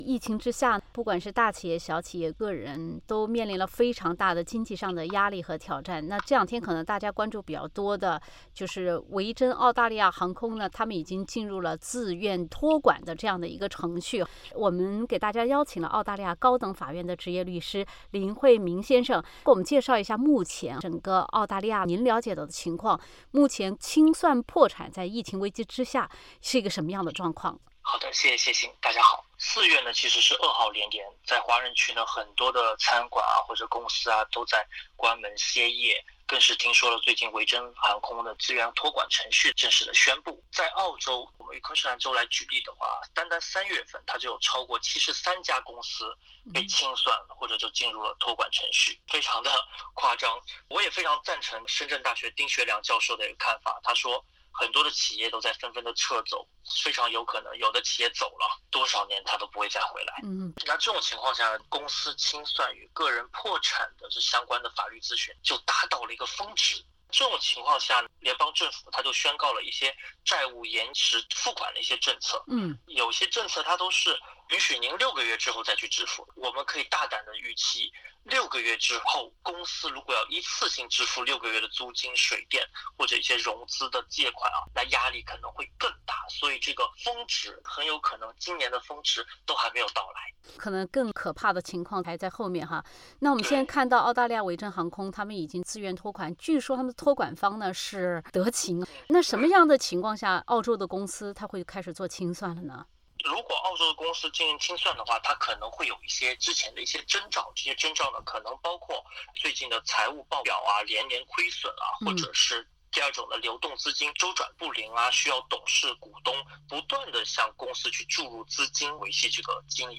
采访一开始